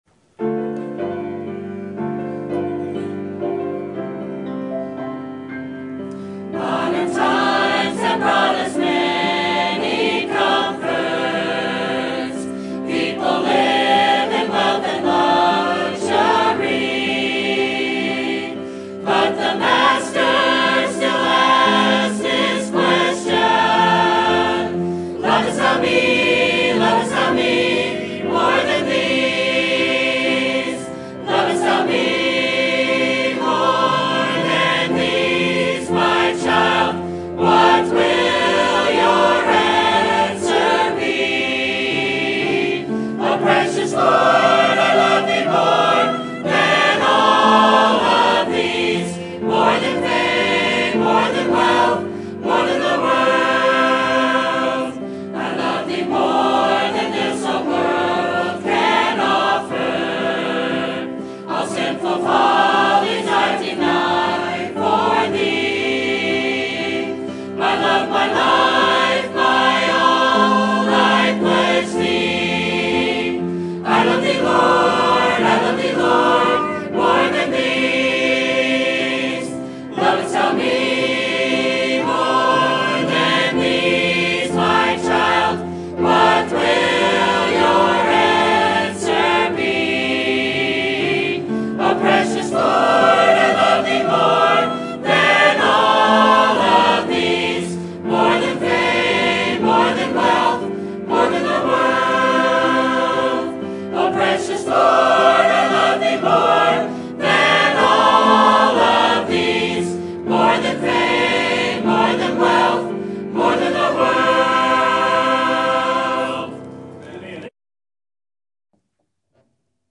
Sermon Topic: Missions Conference Sermon Type: Special Sermon Audio: Sermon download: Download (25.52 MB) Sermon Tags: 1 John Jesus Missions Disciples